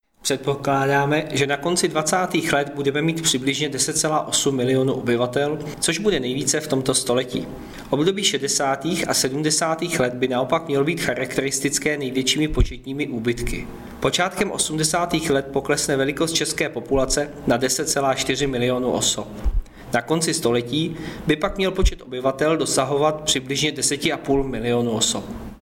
Vyjádření Jaroslava Sixty, místopředsedy Českého statistického úřadu, soubor ve formátu MP3, 1003.1 kB